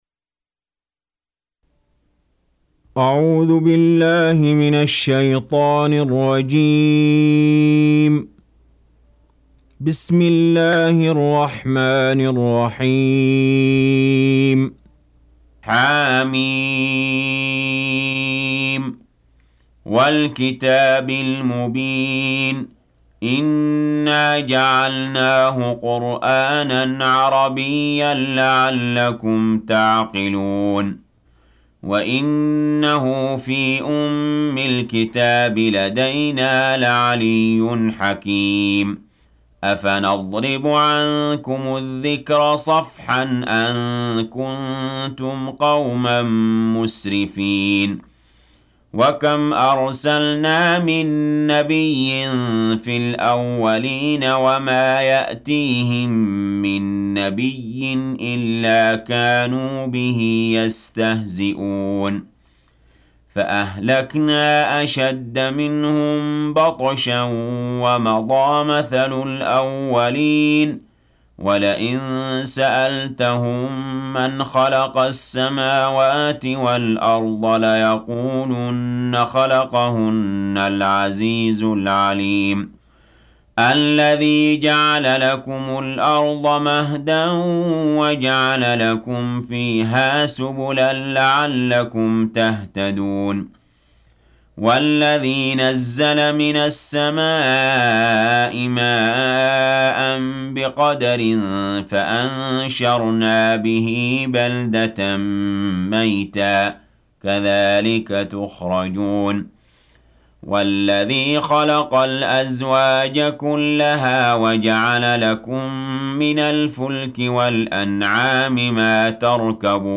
43. Surah Az-Zukhruf سورة الزخرف Audio Quran Tarteel Recitation
Surah Sequence تتابع السورة Download Surah حمّل السورة Reciting Murattalah Audio for 43.